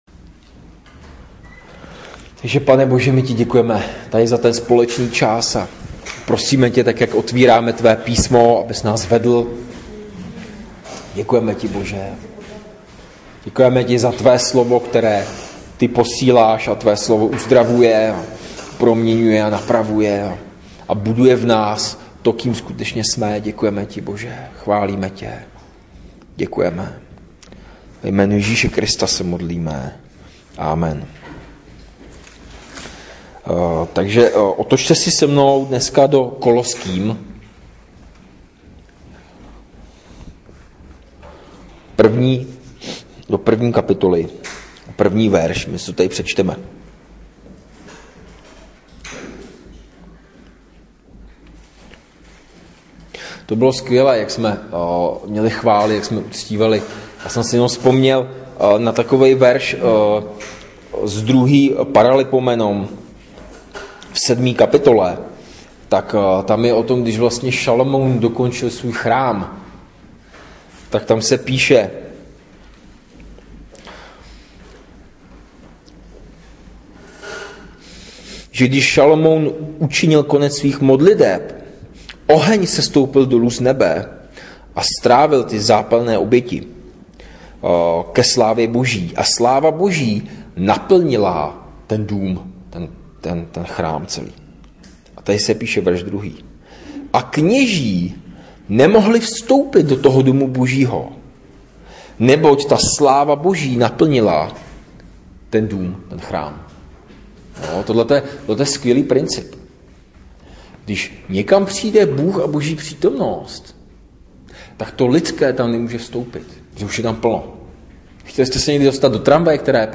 Ze série Koloským (nedělní bohoslužba)